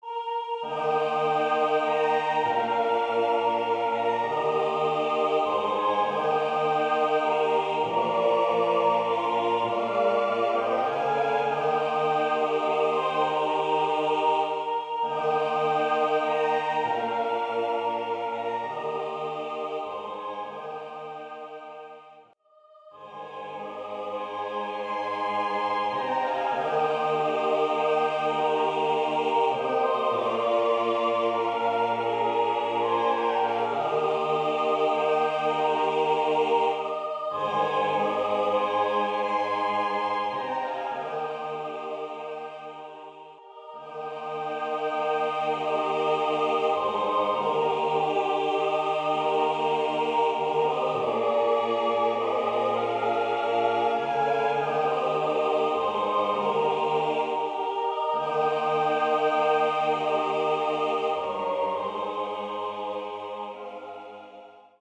Naturjodel 3 teilig))Jodelchor
elektronisch